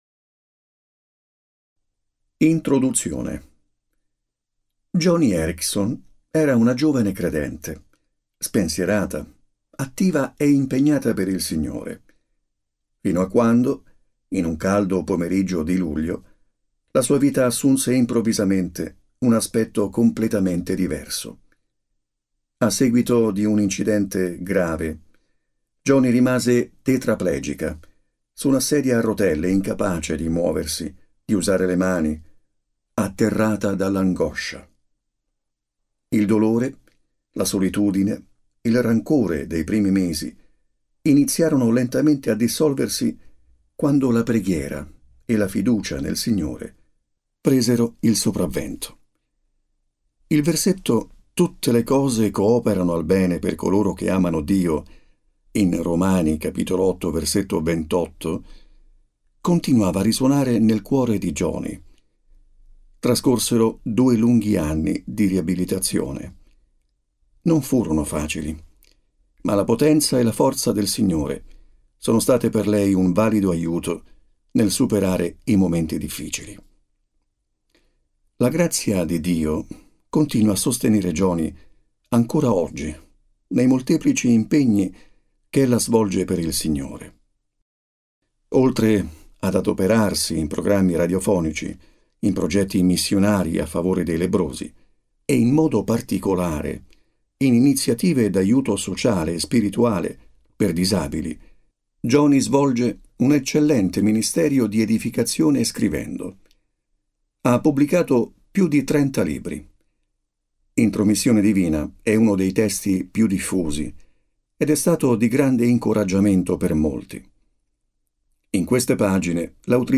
Lettura integrale MP3